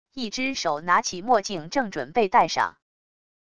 一只手拿起墨镜正准备戴上wav音频